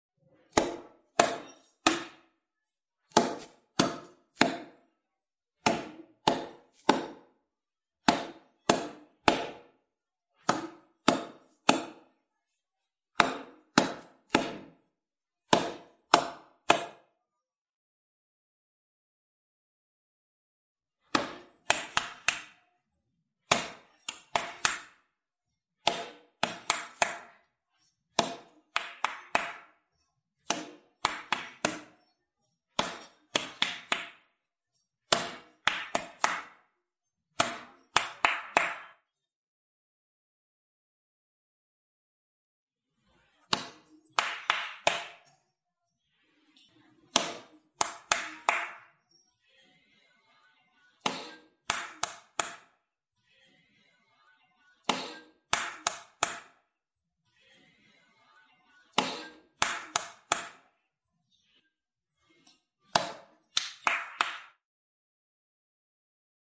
Tiếng Vỗ tay theo tiết tấu Chậm, Nhanh, Phối hợp
Tiếng Vỗ tay theo Phách, Nhịp (Mầm non) Tiếng Vỗ tay mầm non theo tiết tấu phối hợp
Thể loại: Tiếng động
Description: Hiệu ứng âm thanh vỗ tay theo nhịp điệu đa dạng - từ tiết tấu chậm rãi, nhanh sôi động đến phối hợp hòa quyện.
tieng-vo-tay-theo-tiet-tau-cham-nhanh-phoi-hop-www_tiengdong_com.mp3